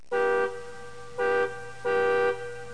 horn2.mp3